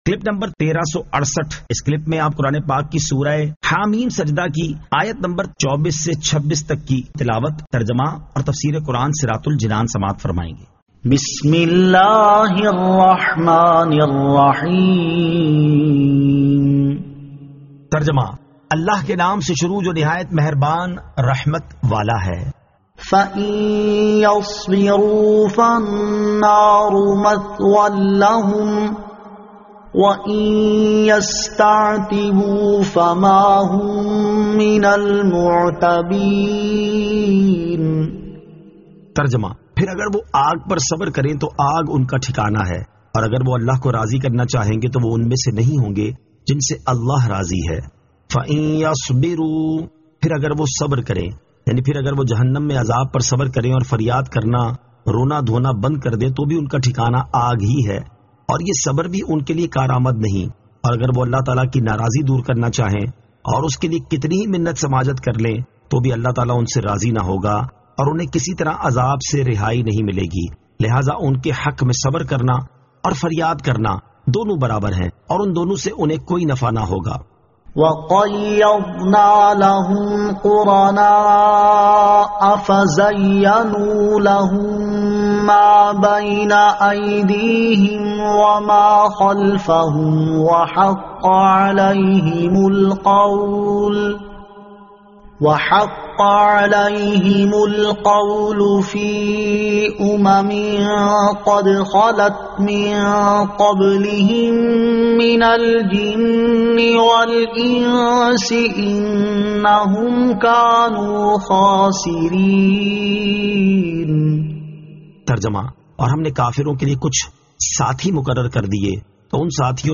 Surah Ha-Meem As-Sajdah 24 To 26 Tilawat , Tarjama , Tafseer